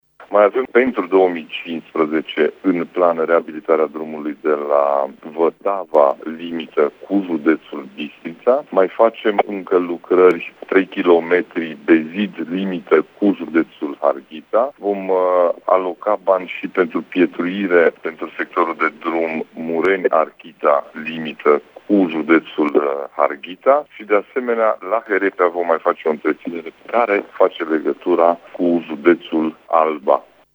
Ciprian Dobre a arătat că CJ Mureș va mai reabilita în acest an drumuri județene care fac legătura cu județele Bistrița- Năsăud, Harghita și Alba: